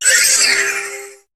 Cri de Cosmog dans Pokémon HOME.